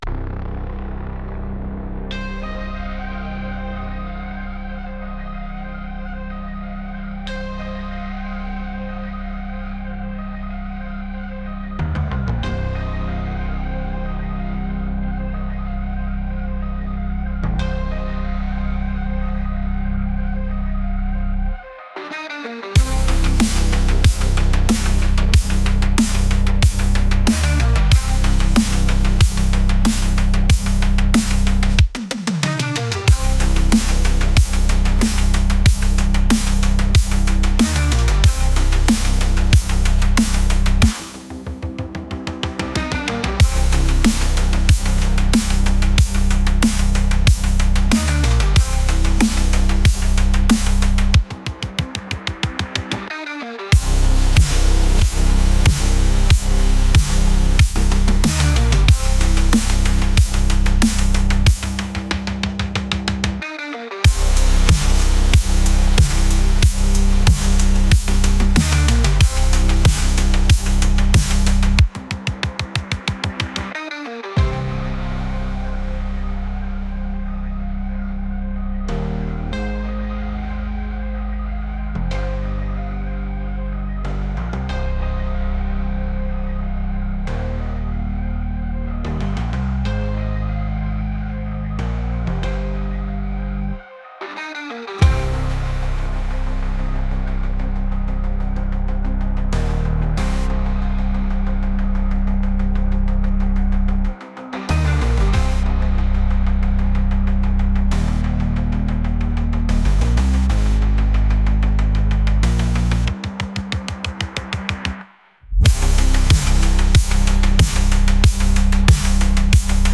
High-Energy Electronic Dance Track